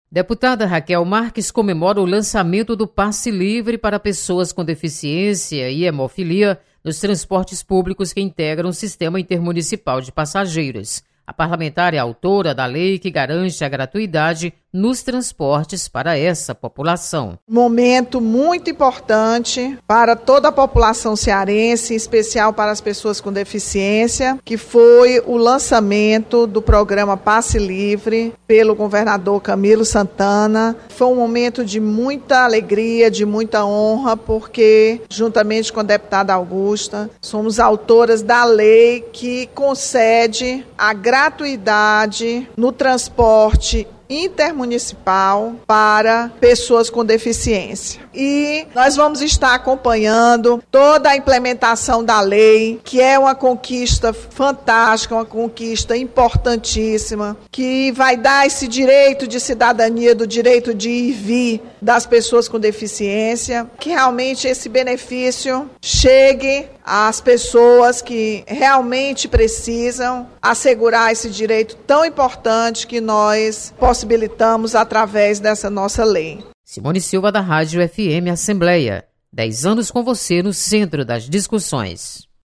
Deputada Rachel Marques destaca ampliação do Passe Livre. Repórter